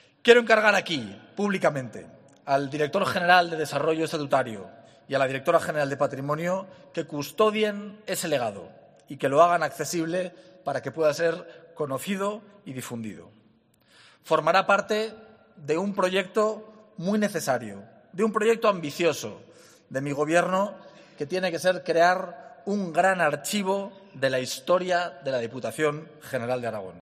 Hoy s eha celebrado el acto institucional de celebración del Día de Aragón en Huesca
DISCURSO